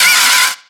Cri de Venipatte dans Pokémon X et Y.